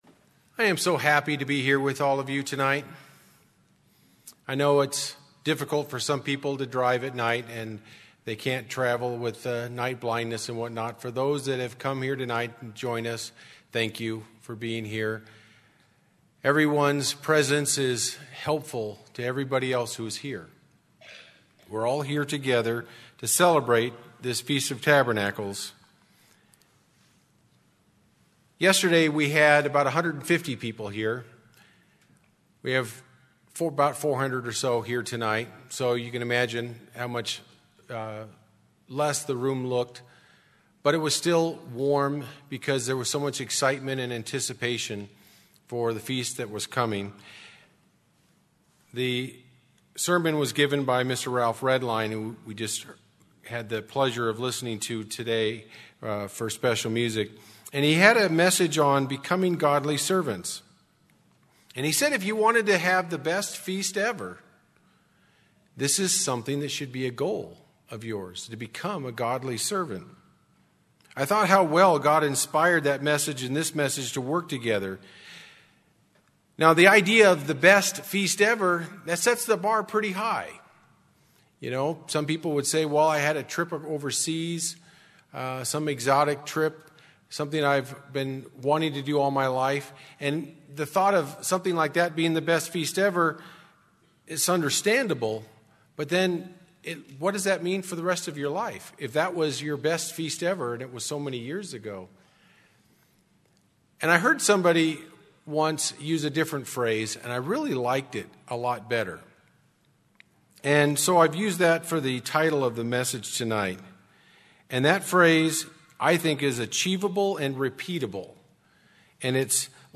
This sermon was given at the Bend-Redmond, Oregon 2018 Feast site.